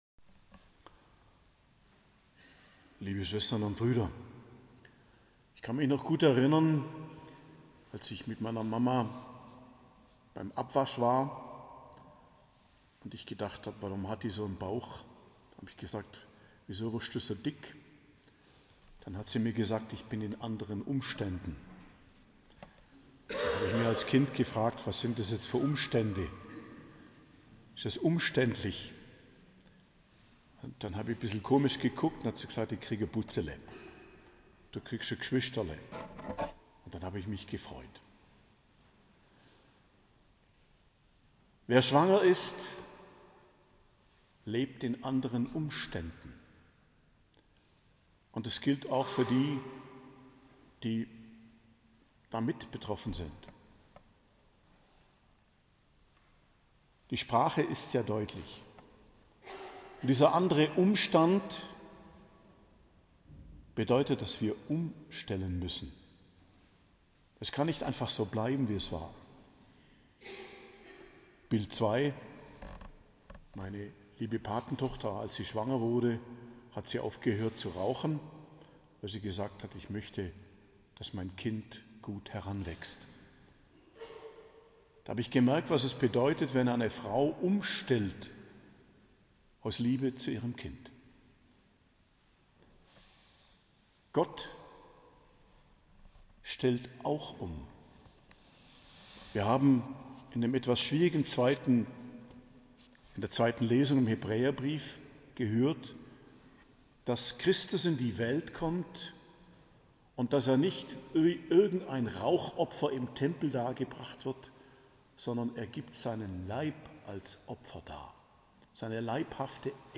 Predigt zum 4. Adventssonntag, 22.12.2024